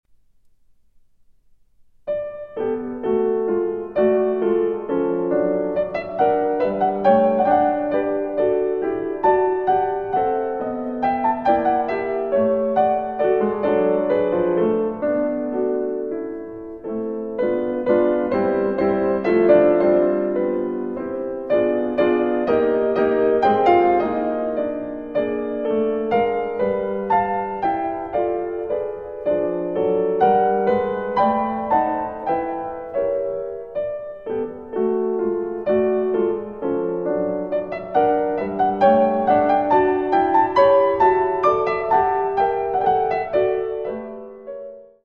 Amabile e teneramente con moto